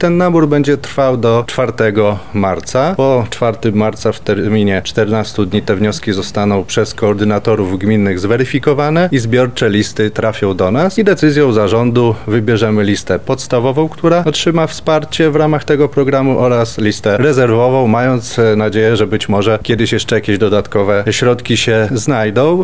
marek wojciechowski – mówi wicemarszałek Marek Wojciechowski.